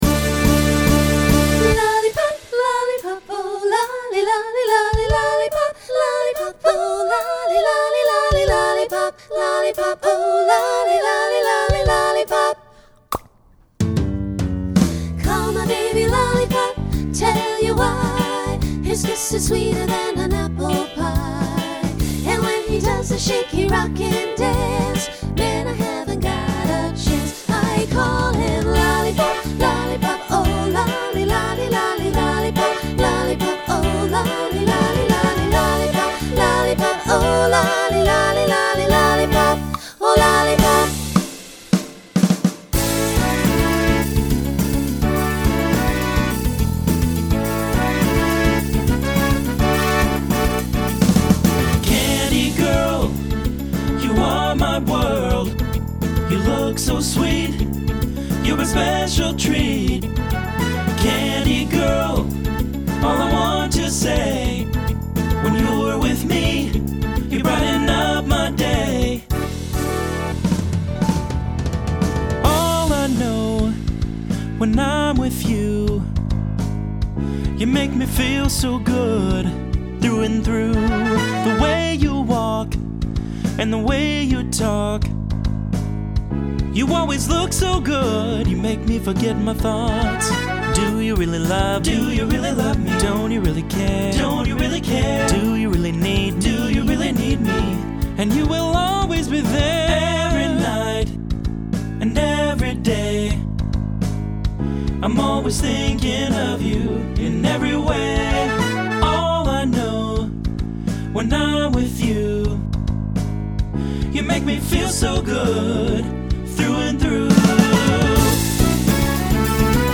SSA trio
SSA-SATB
Genre Pop/Dance
Voicing Mixed